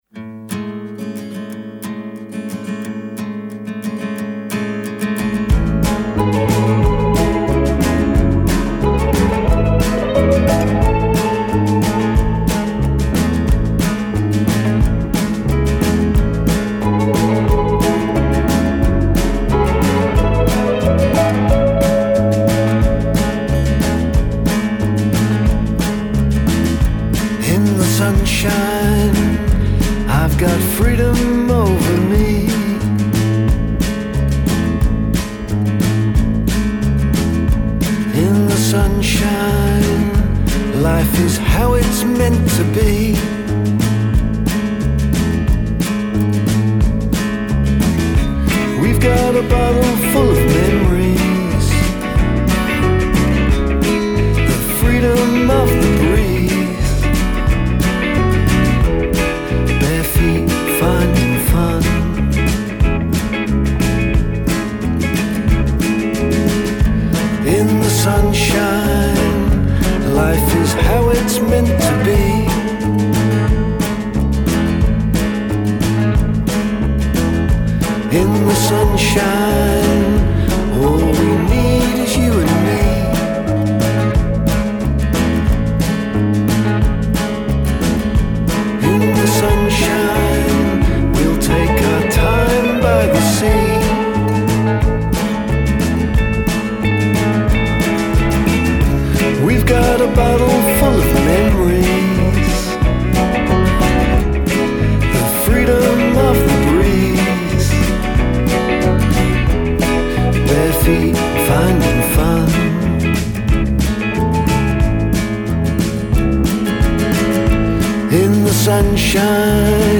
fun, upbeat Jazz Country single
there are hints of JJ Cale, and old school Jazz
Drums
Bass Guitar